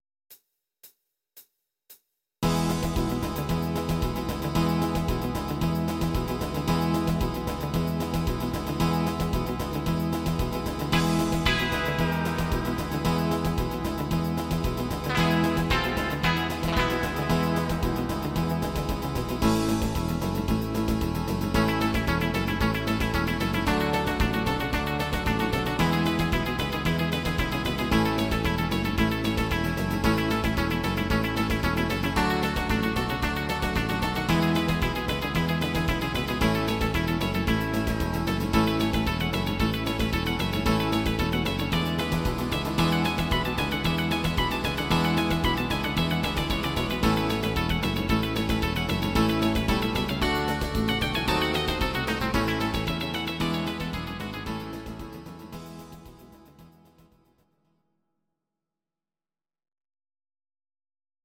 These are MP3 versions of our MIDI file catalogue.
instr. Gitarre